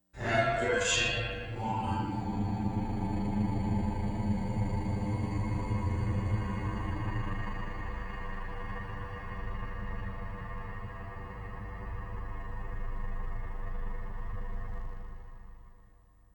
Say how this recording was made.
Stereo Reduction